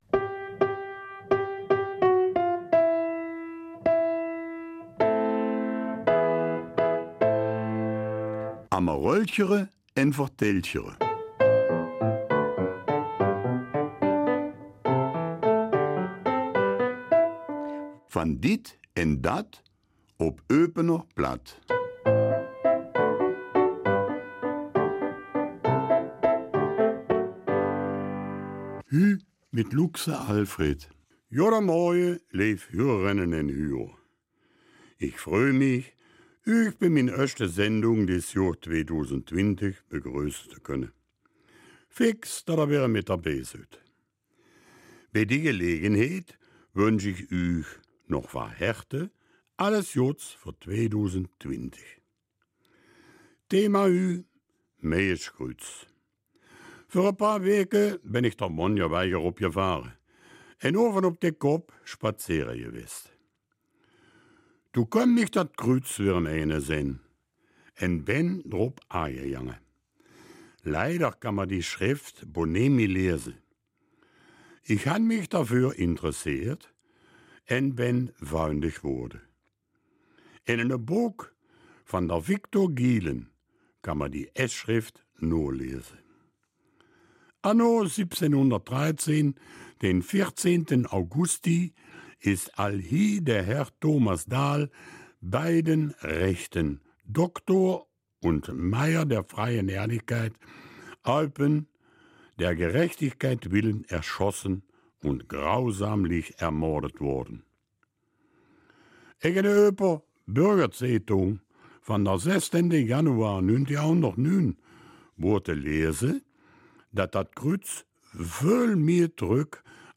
Eupener Mundart - 26. Januar